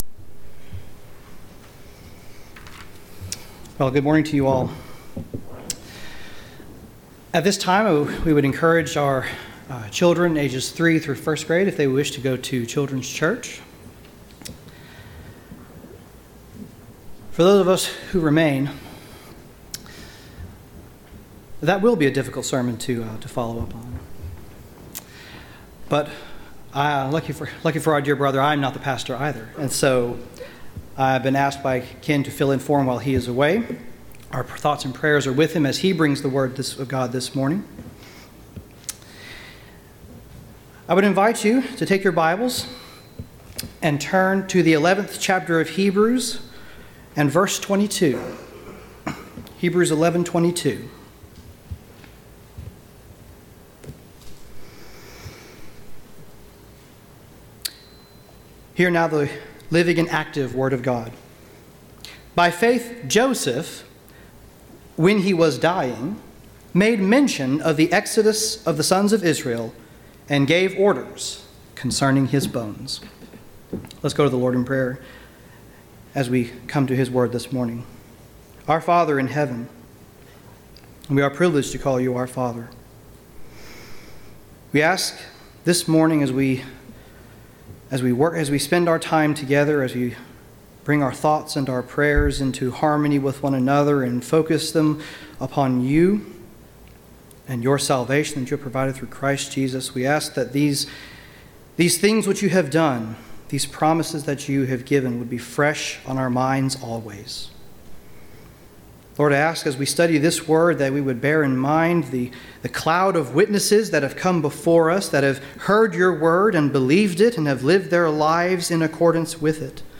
Passage: Hebrews 11:22 Service Type: Sunday AM